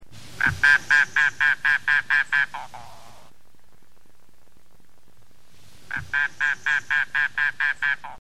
Florida Bird Sounds – Ornithology Collection
Mangrove Cuckoo
Coccyzus minor  Short